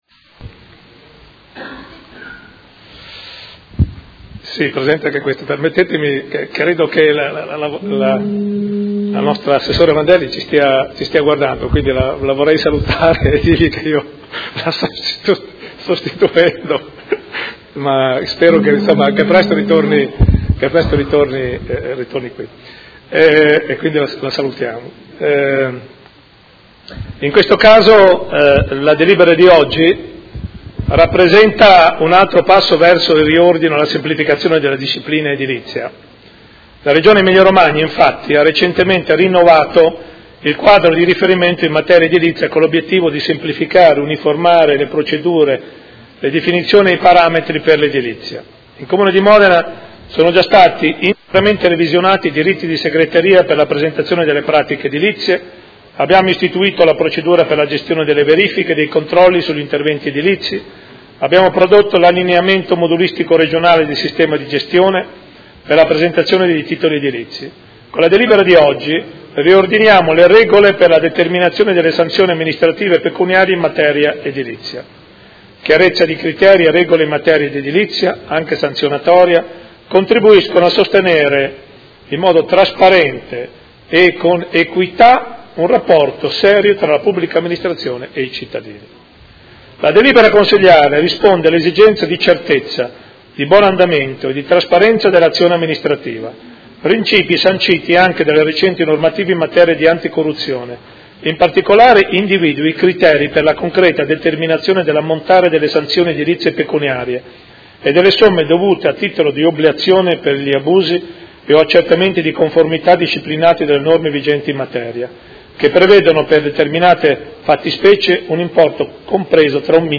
Seduta del 15/02/2018. Proposta di deliberazione: Determinazione delle sanzioni amministrative e pecuniarie in materia edilizia – Riordino sistema sanzionatorio e adeguamento alla normativa vigente
Sindaco